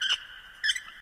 lamp_squeaks_1.ogg